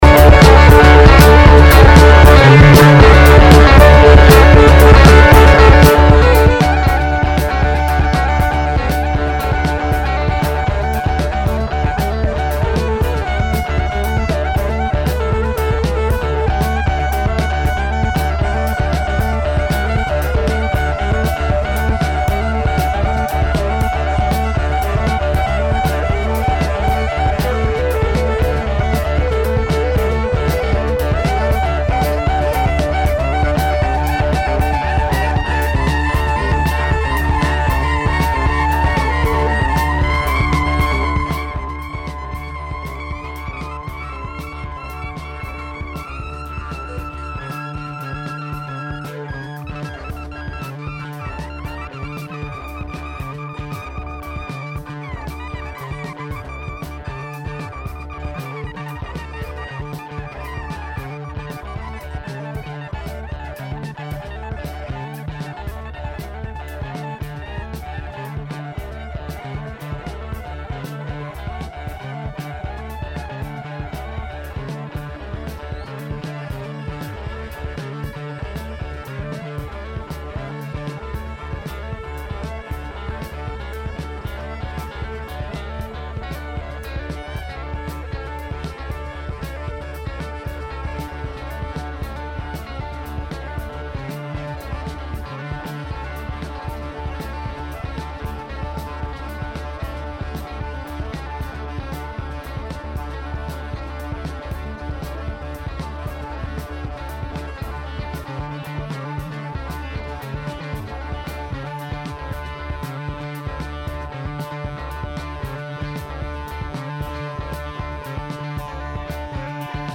April 29, 2024: A brief on the encampment on Deering Meadow, music directing at Northwestern, emotional support water bottles, and a new episode of the B-List. WNUR News broadcasts live at 6 pm CST on Mondays, Wednesdays, and Fridays on WNUR 89.3 FM.